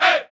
Vox (Mustard-Ey).wav